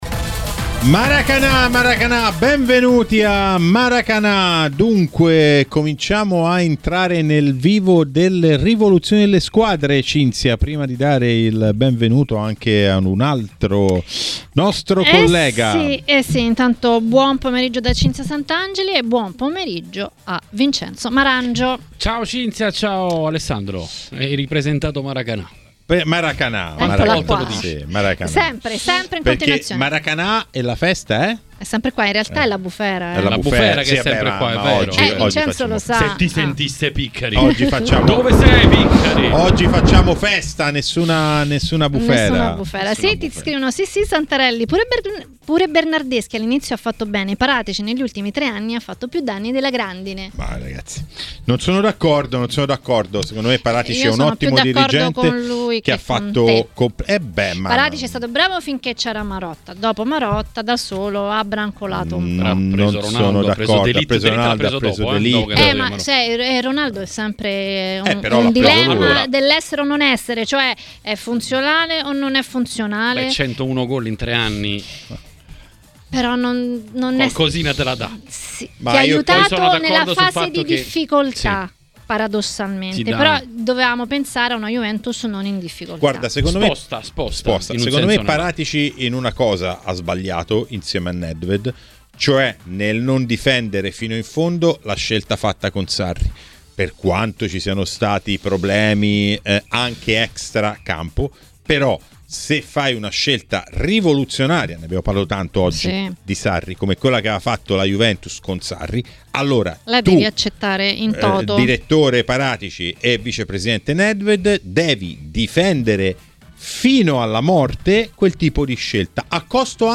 a TMW Radio, durante Maracanà, ha parlato del momento della Fiorentina.